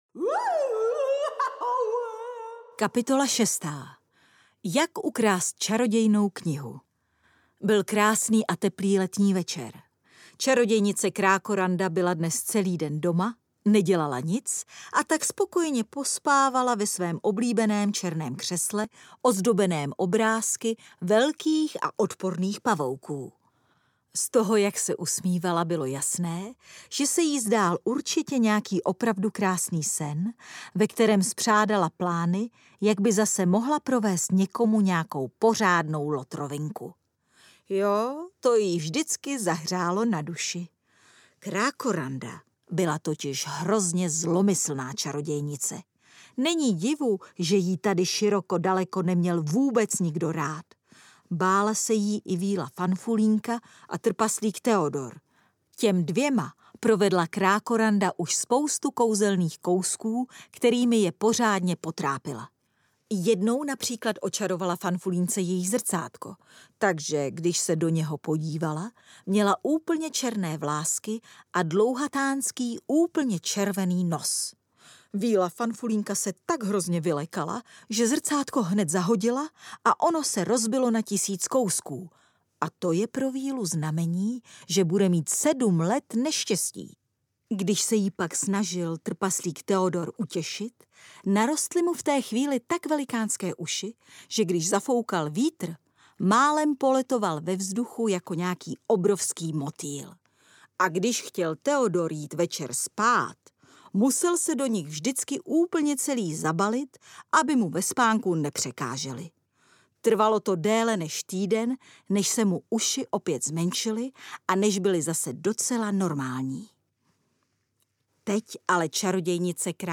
Strašidelný kongres audiokniha
Ukázka z knihy
• InterpretNela Boudová